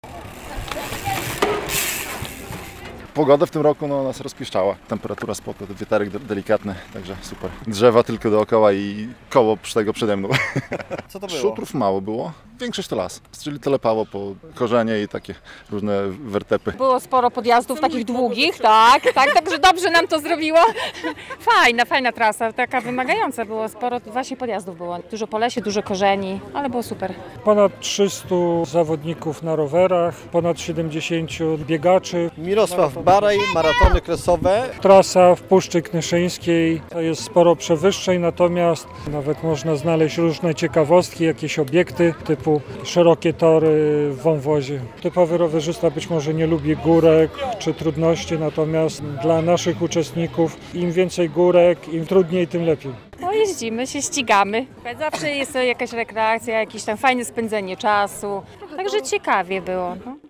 Po raz 11. wystartowały "Maratony Kresowe" po Puszczy Knyszyńskiej - relacja